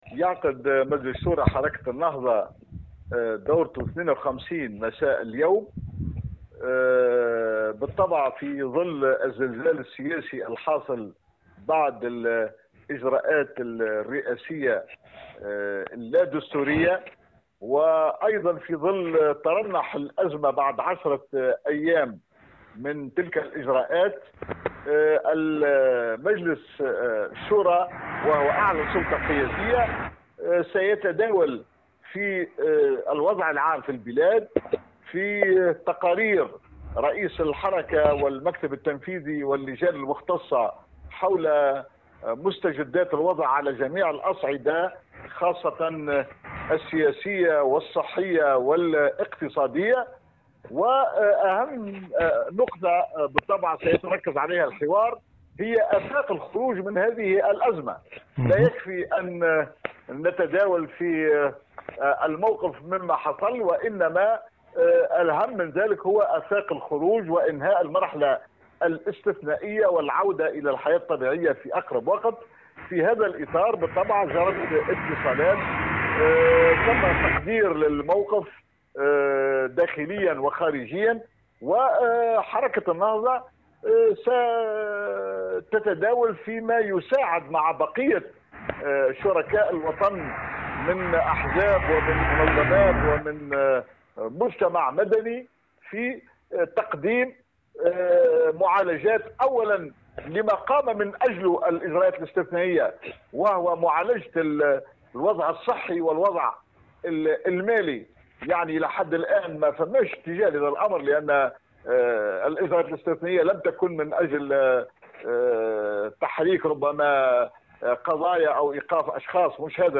وبين القوماني في تصريح لمراسل الجوهرة اف أم، اليوم الأربعاء، أن شورى الحركة ستنظر في آفاق الخروج من الازمة واستعادة البرلمان لنشاطه وآفاق إعداد البلاد لانتخابات سابقة لأوانها .